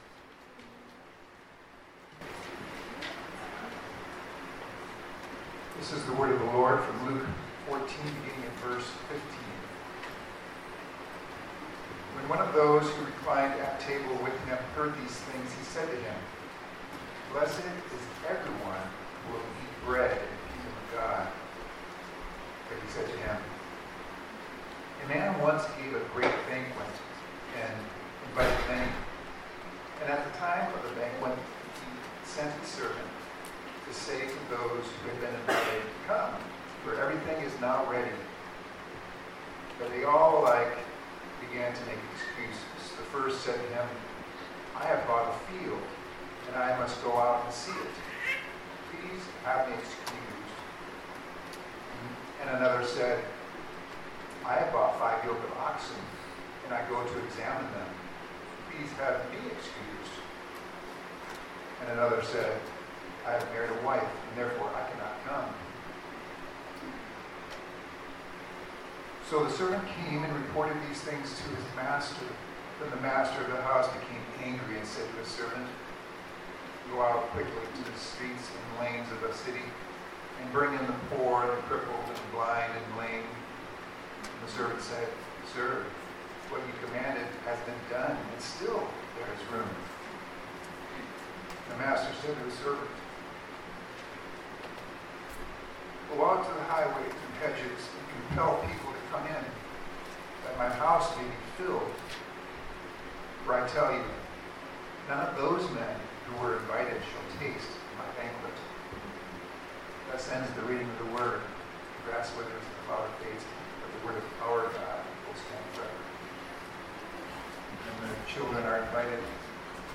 Luke's Parables Passage: Luke 14:15-24 Service Type: Sunday Morning Topics